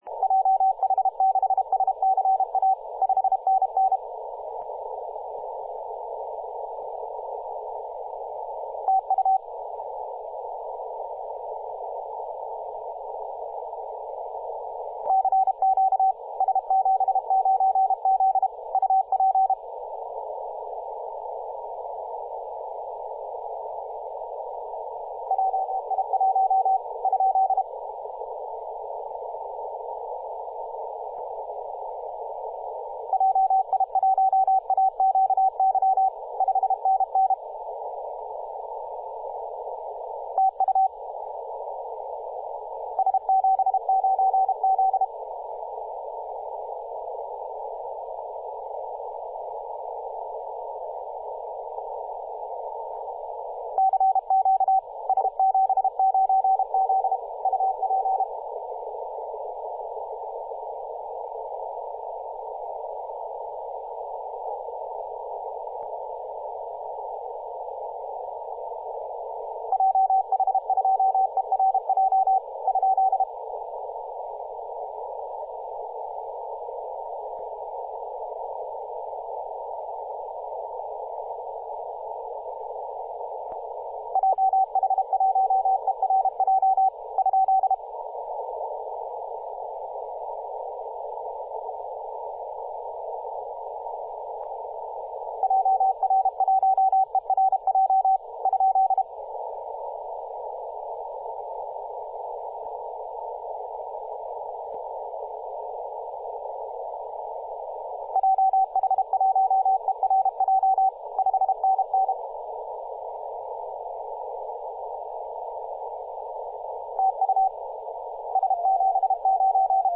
2017/Apr/09 0542z S79Z 21.002MHz CW